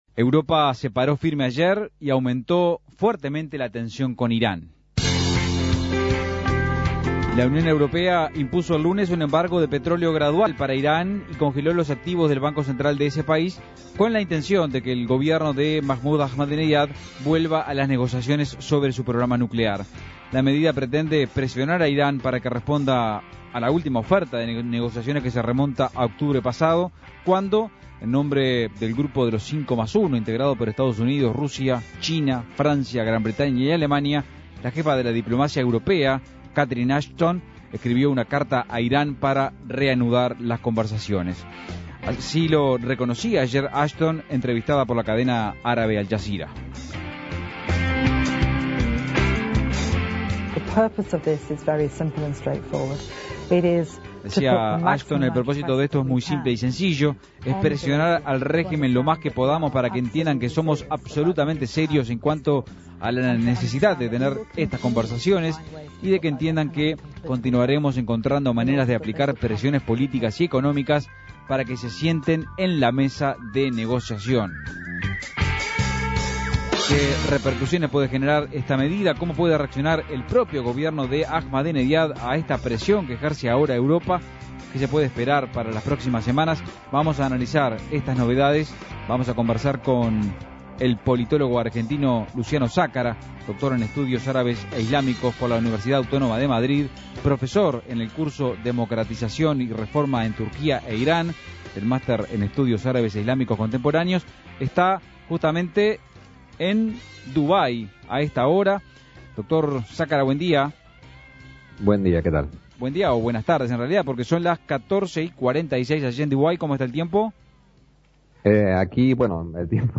Escuche el contacto con el politólogo